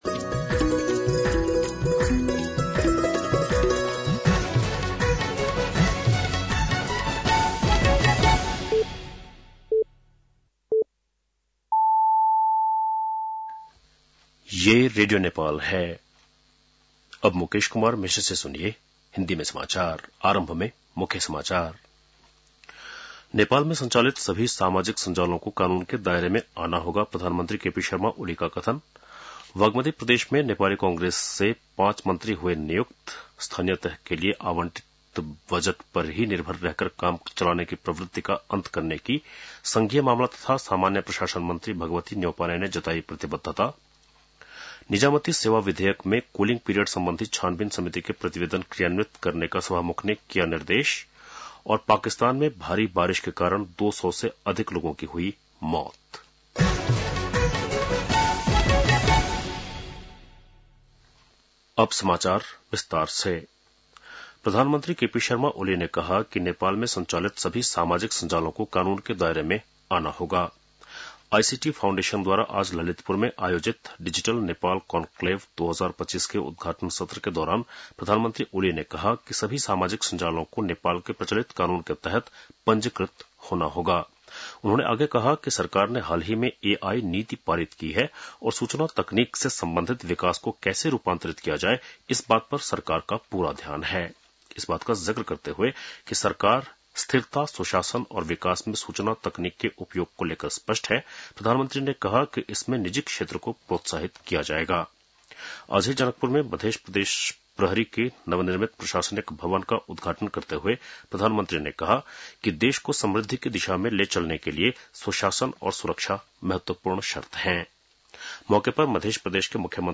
बेलुकी १० बजेको हिन्दी समाचार : ३० साउन , २०८२